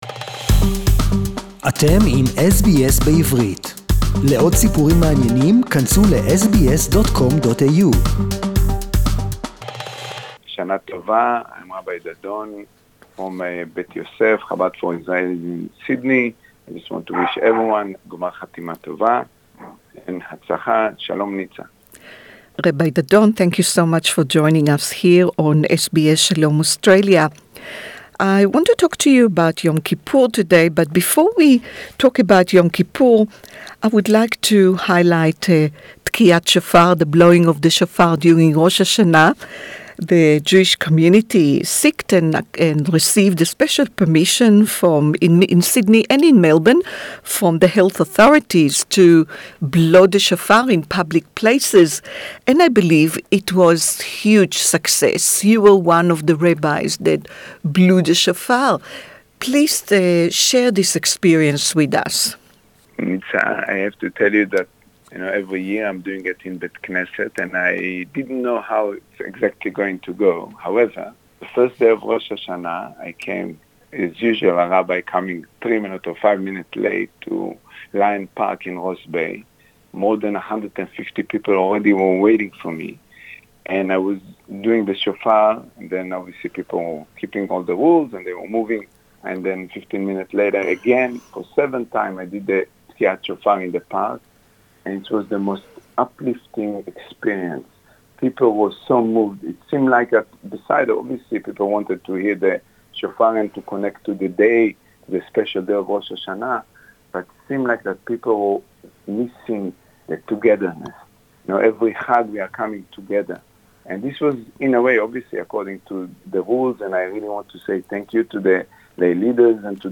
(Interview in English) The Rabbi was one of the rabbis who received a special permission from the NSW Health Minister to blow the shofar during Rosh Hashana in public parks around Sydney. We will talk to him about this exciting unique experience and we will find out the challenges of observing Yom Kippur under lockdown